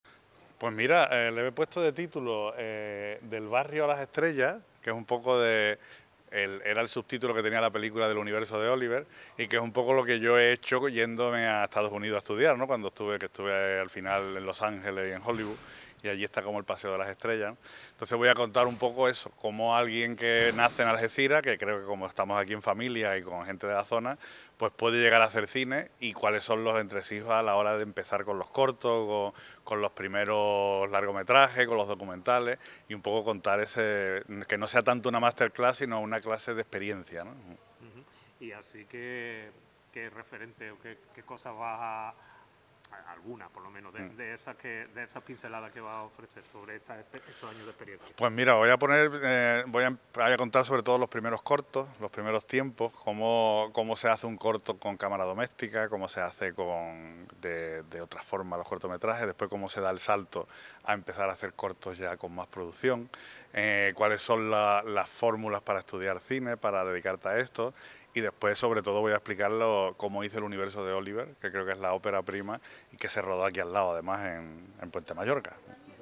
en San Roque tuvo su broche de oro este jueves en el Teatro Juan Luis Galiardo
charla